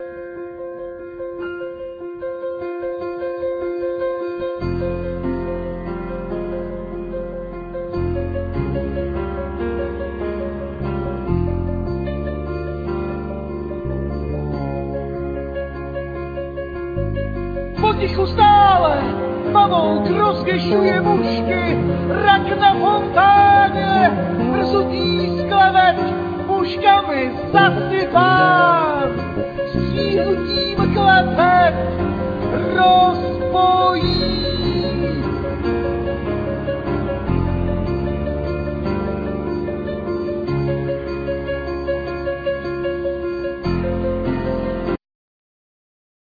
Vocal,Trumpet,Tuboflaut,Backing vocal
Piano,Keyboard,Cymbals,Backing vocal
Drums,Persussions
Clarinet,Bariton sax,Alt sax,Backing vocal
El.guitar
Double bass